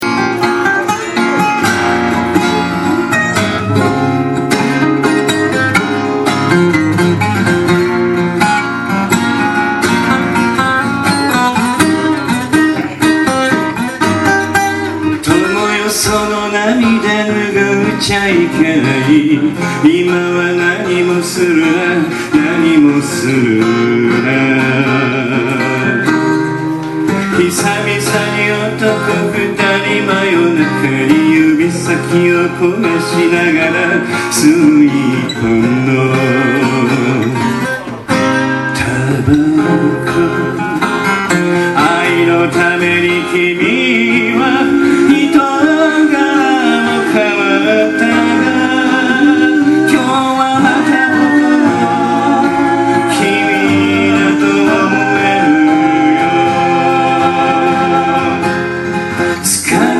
ACOUSTIC NIGHT